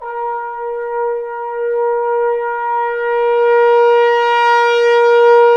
Index of /90_sSampleCDs/Roland L-CDX-03 Disk 2/BRS_Bone Sec.FX/BRS_Bone Sec.FX